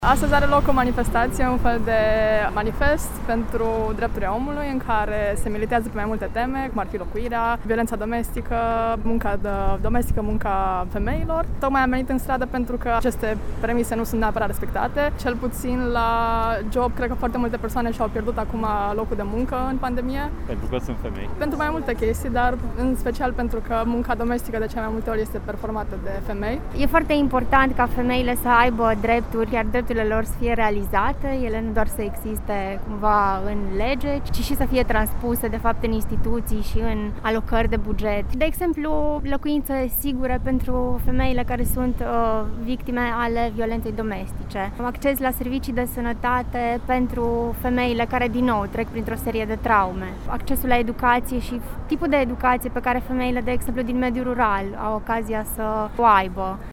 8 martie a adus anul acesta la Cluj, în piațeta din fața prefecturii, câteva zeci de persoane, care au militat pentru un mai mare respect al drepturilor femeilor. Stoparea abuzurilor împotriva femeilor și accesul la servicii de sănătate și de locuire decente, au fost printre principalele revendicări.
Voxuri-femei-8-martie.mp3